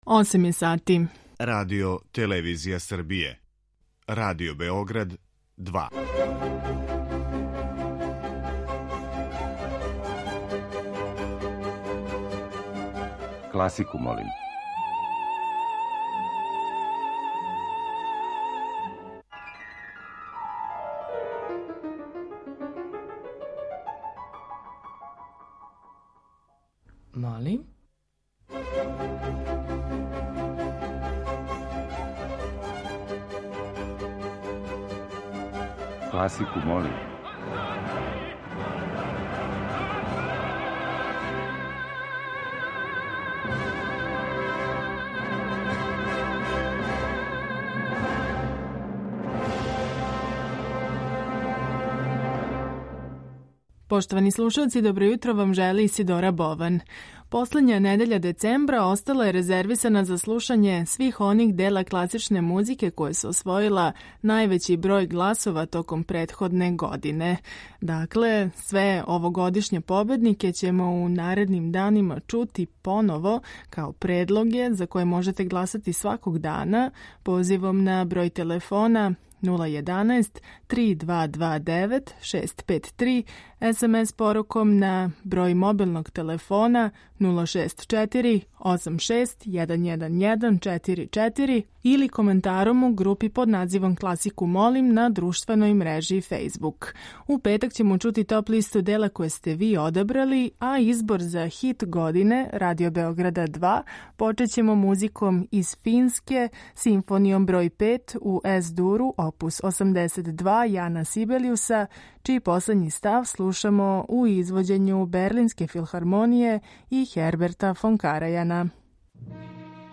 Избор за топ-листу класичне музике Радио Београда 2